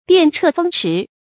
電掣風馳 注音： ㄉㄧㄢˋ ㄔㄜˋ ㄈㄥ ㄔㄧˊ 讀音讀法： 意思解釋： 形容像閃電刮風一樣迅速。